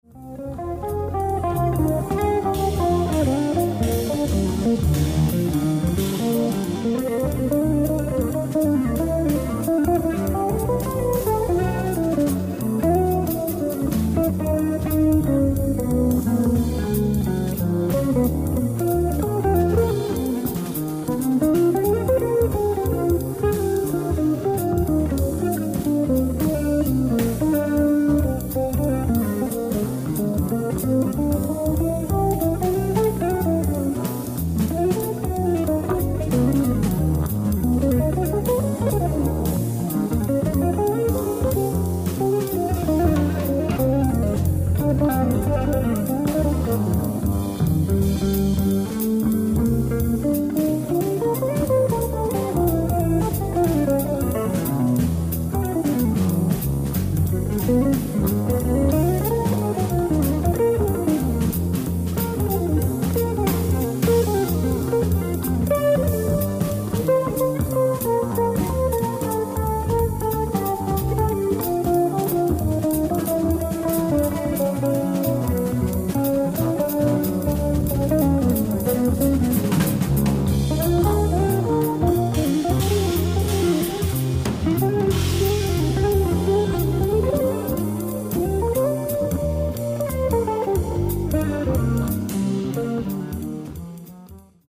ライブ・アット・ブレーメン、ドイツ
※試聴用に実際より音質を落としています。